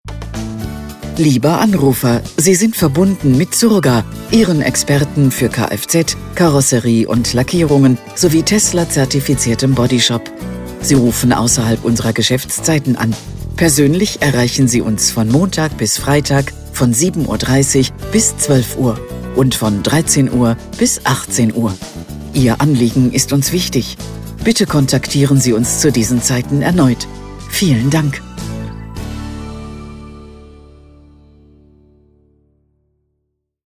Telefonansagen mit echten Stimmen – keine KI !!!